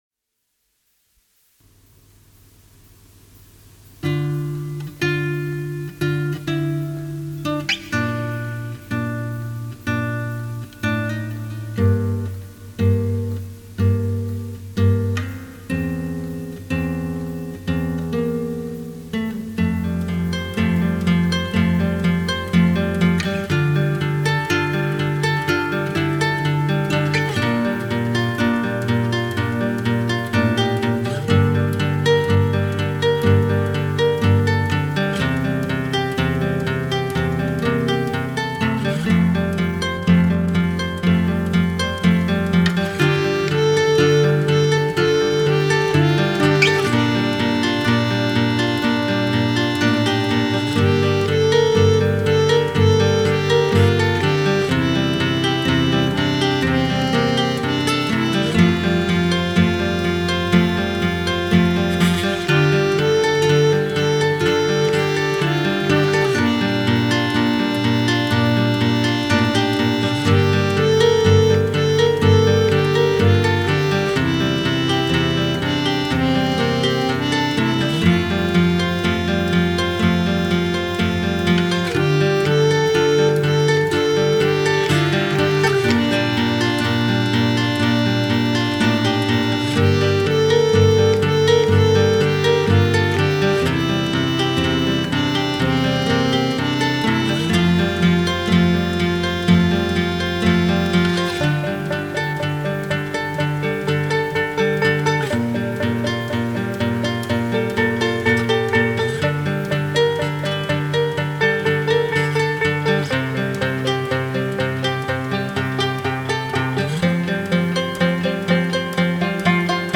evocative rustic folk